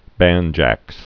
(bănjăks)